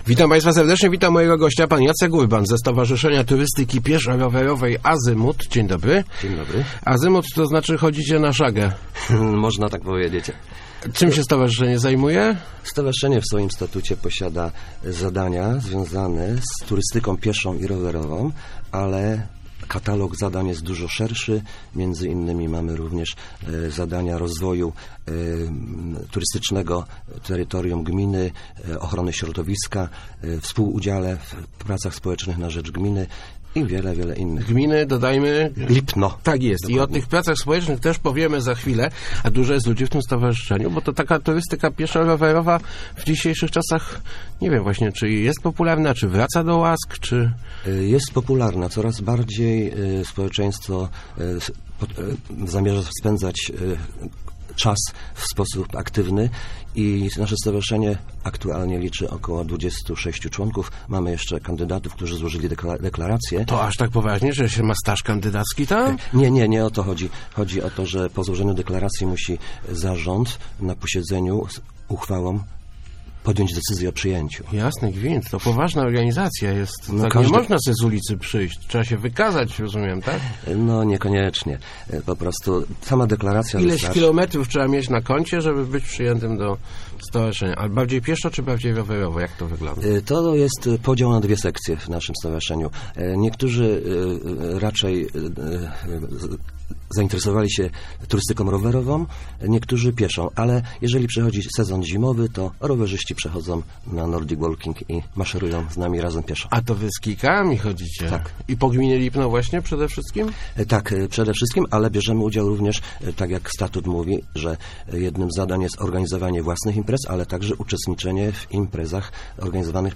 Start arrow Rozmowy Elki arrow Zalew w Mórkowie